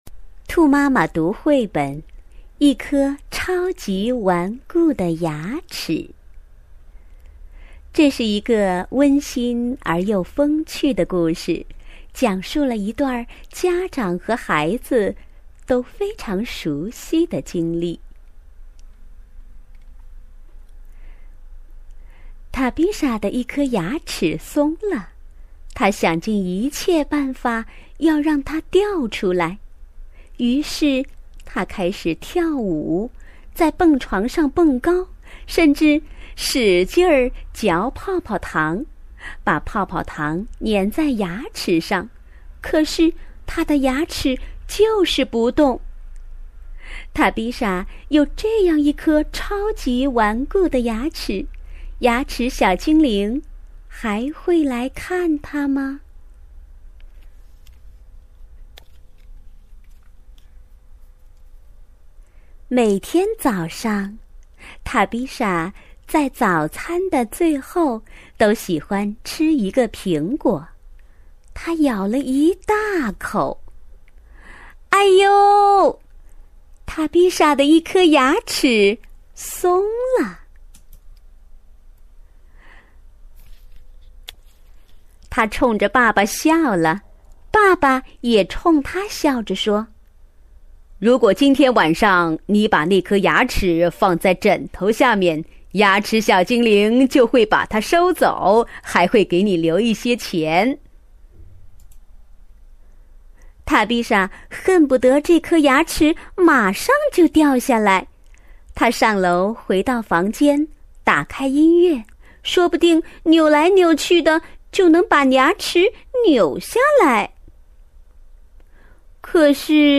首页>mp3 > 儿童故事 > 一颗超级顽固的牙齿